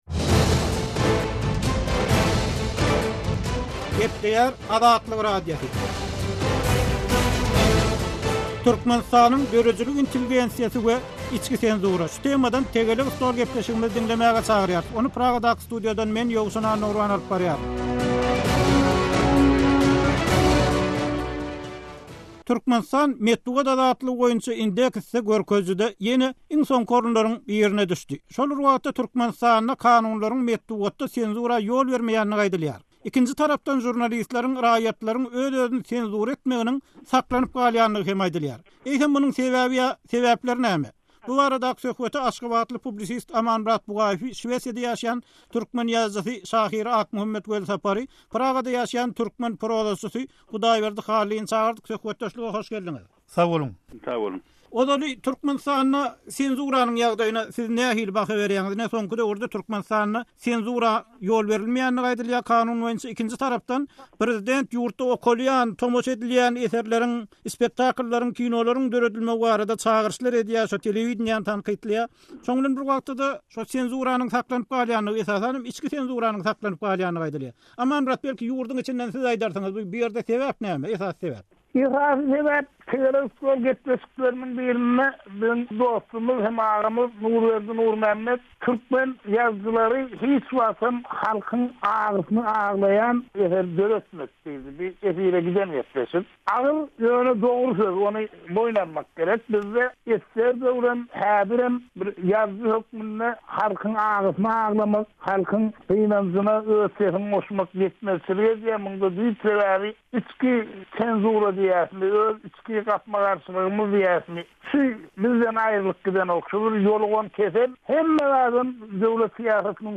Türkmenistanda täze kabul edilen Metbugat kanunynyň senzura ýol bermeýändigi, emma žurnalistleriň, dörediji adamlaryň gorkudan çykyp bilemýändigi, netijede içki senzuranyň saklanyp galýandygy aýdylýar. Azatlyk Radiosynyň nobatdaky “Tegelek stol” söhbetdeşliginde Türkmenistanda gadaganlyga düşen ýazyjylar döredijilik erkinligine, pikir azatlygyna böwet bolýan sebäpler barada öz pikirlerini aýdýarlar.